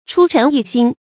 出陈易新 chū chén yì xīn
出陈易新发音